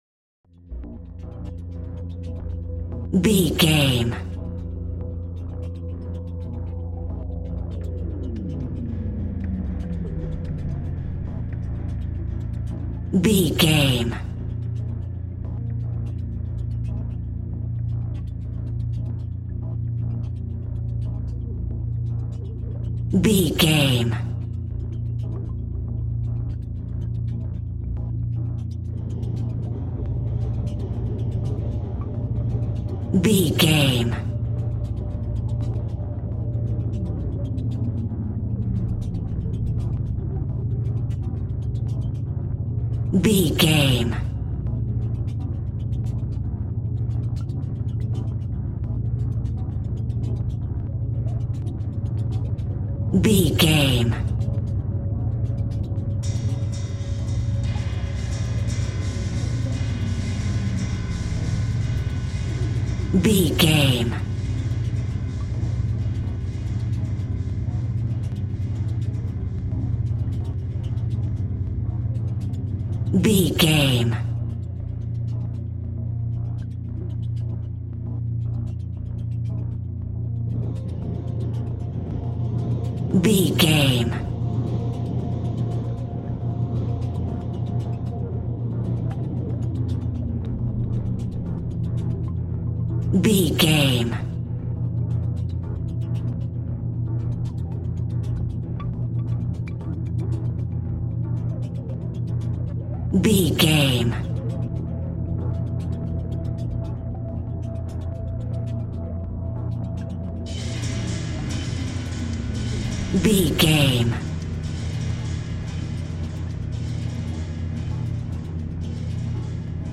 In-crescendo
Atonal
ominous
suspense
haunting
eerie
synthesiser
percussion
Horror Ambience
dark ambience
Synth Pads
Synth Ambience